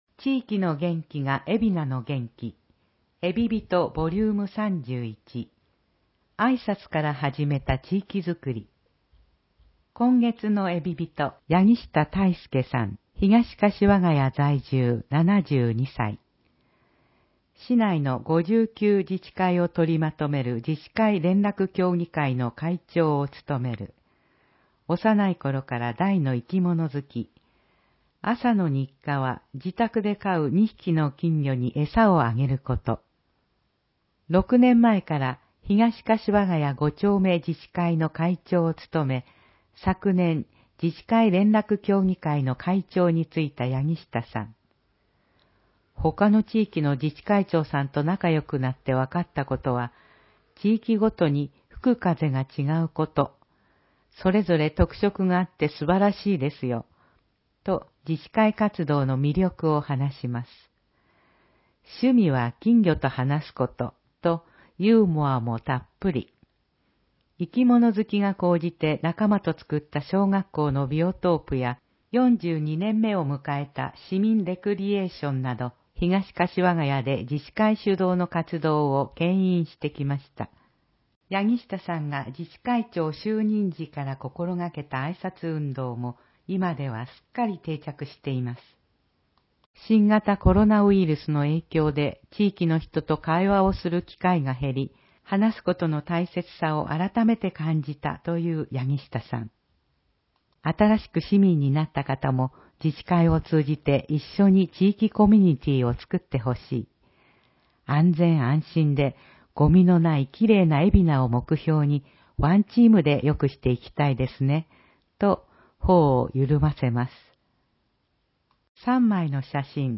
広報えびな 令和2年7月15日号（電子ブック） （外部リンク） PDF・音声版 ※音声版は、音声訳ボランティア「矢ぐるまの会」の協力により、同会が視覚障がい者の方のために作成したものを登載しています。